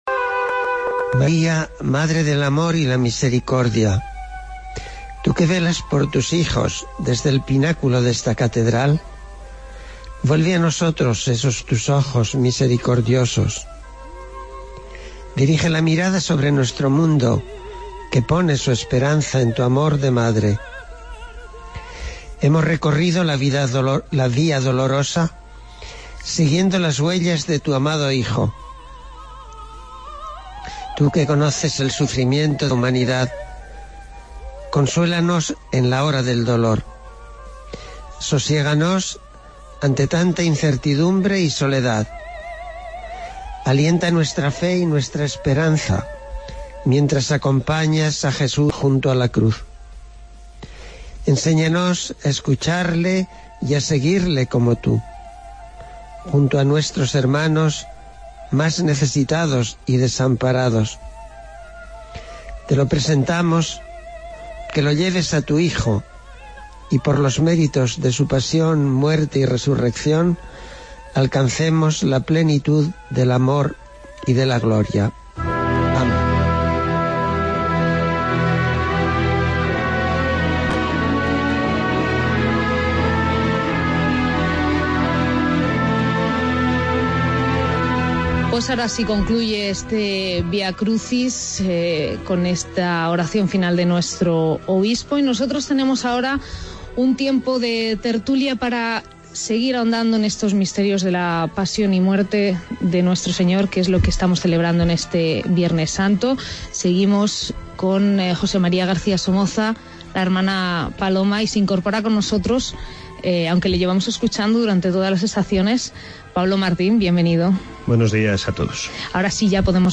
AUDIO: Vía Crucis de Penitencia desde Avila